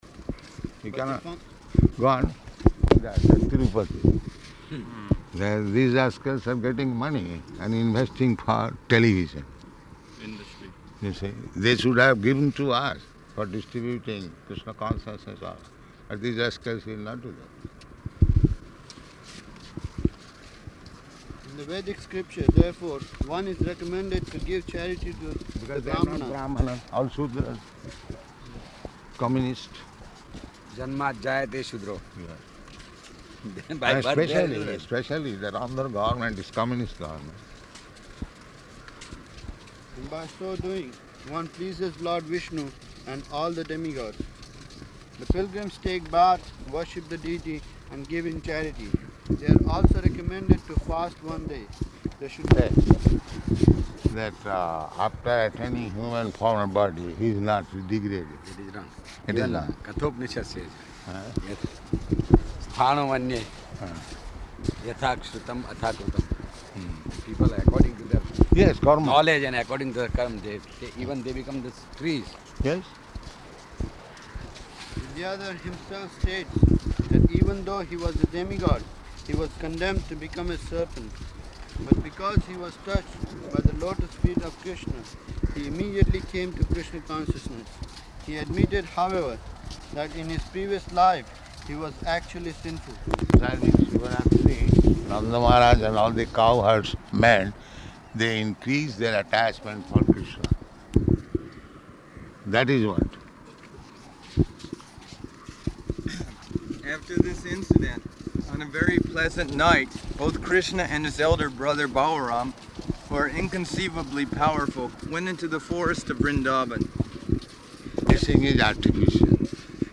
Morning Walk
Type: Walk
Location: Bombay